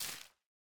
Minecraft Version Minecraft Version snapshot Latest Release | Latest Snapshot snapshot / assets / minecraft / sounds / block / leaf_litter / place4.ogg Compare With Compare With Latest Release | Latest Snapshot